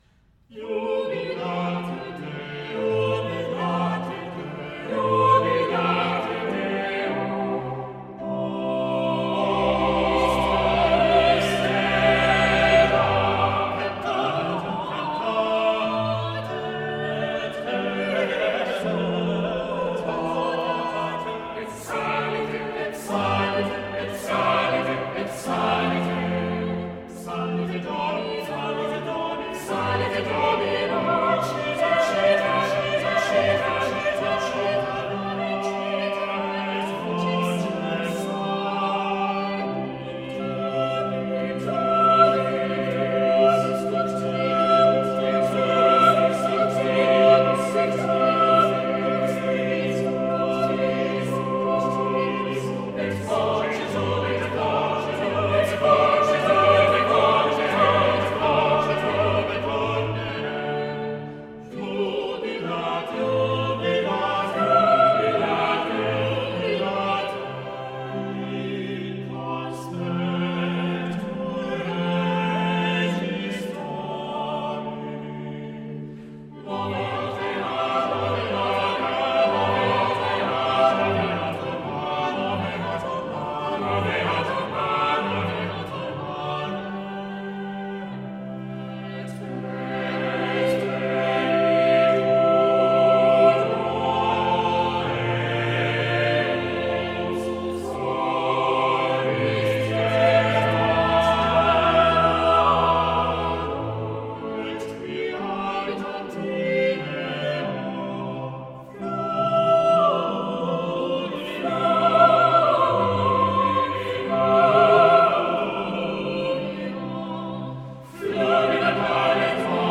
Voicing: "SATB Triple Choir"